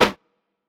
Clap mpc.wav